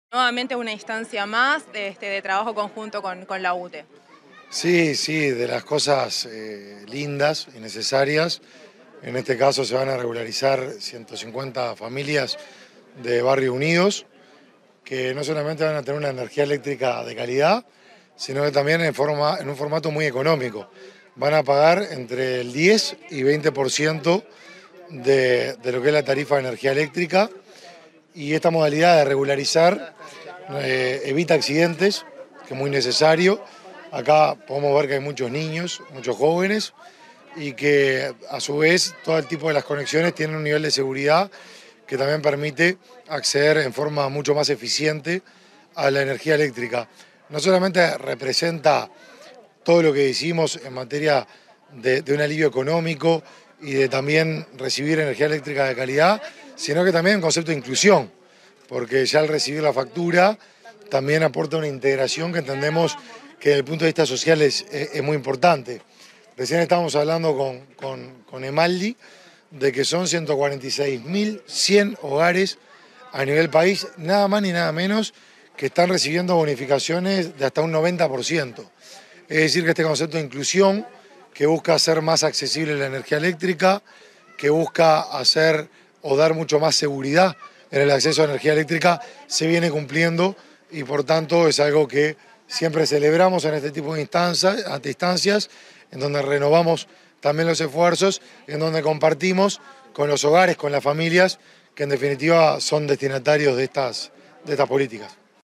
Entrevista al ministro de Desarrollo Social, Martín Lema
Entrevista al ministro de Desarrollo Social, Martín Lema 29/03/2023 Compartir Facebook X Copiar enlace WhatsApp LinkedIn Tras participar en la inauguración de obras de electrificación de UTE, en el marco del Programa de Inclusión Social, este 29 de marzo, el ministro de Desarrollo Social, Martín Lema, realizó declaraciones a Comunicación Presidencial.